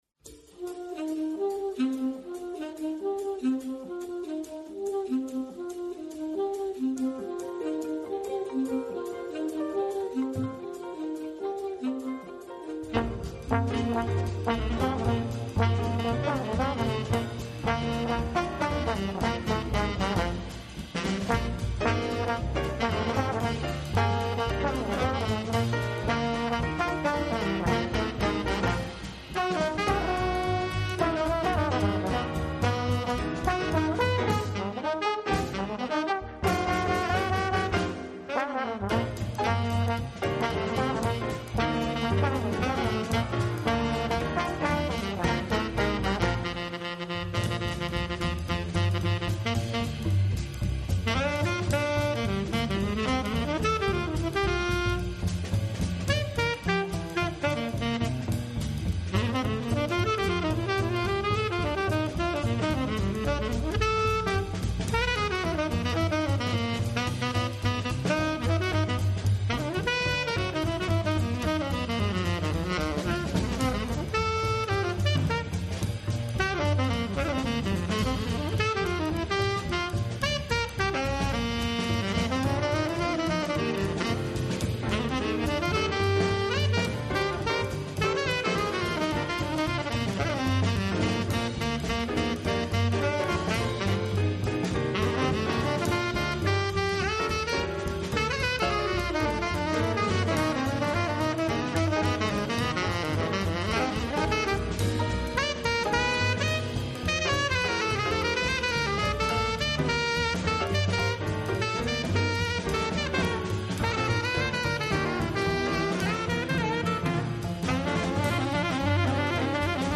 Het promoten van Nederlandse muziek rondom genres jazz, global en meer staat hierbij centraal.
InJazz Radio maakt zelf liveopnames op locatie, zendt daarnaast opnames uit gemaakt tijdens de inJazz festivalavonden en andere evenementen rond Nederlandse, jazz- en globalgerelateerde muziek.